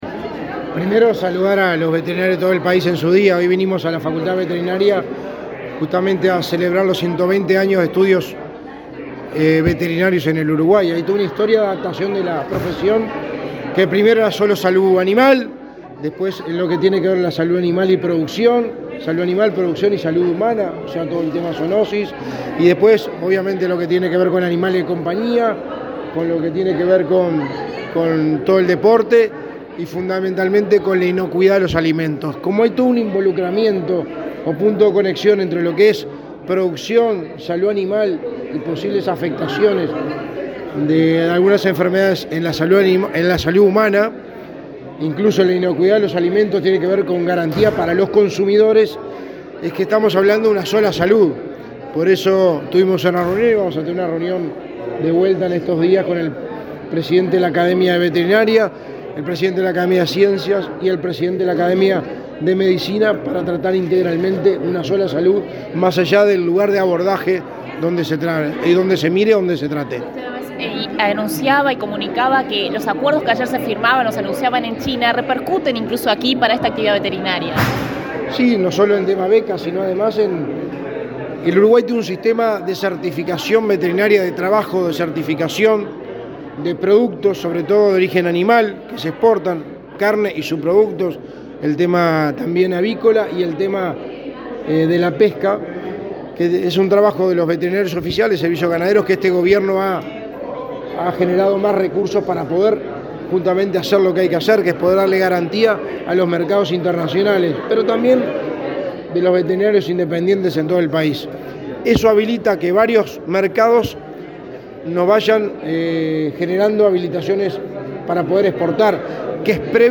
Declaraciones del secretario de Presidencia, Álvaro Delgado
Luego, dialogó con la prensa.